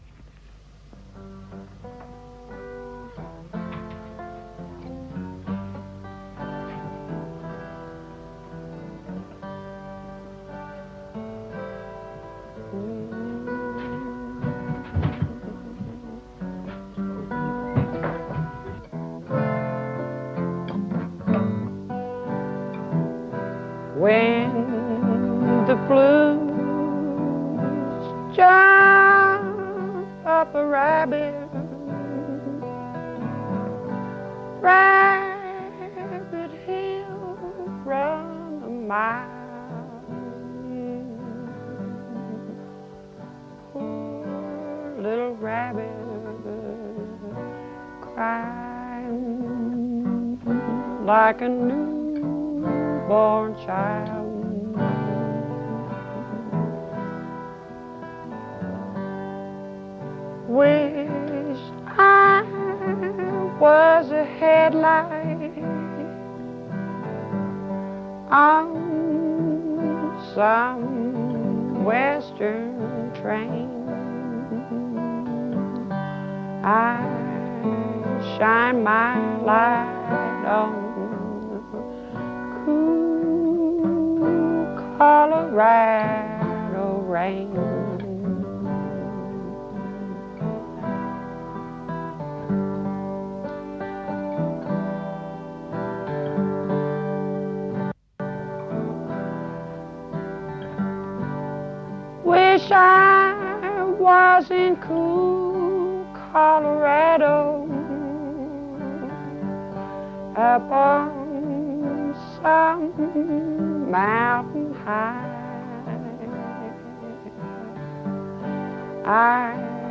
album live